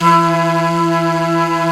Index of /90_sSampleCDs/Roland LCDP04 Orchestral Winds/FLT_Jazz+Singin'/FLT_Singin'Flute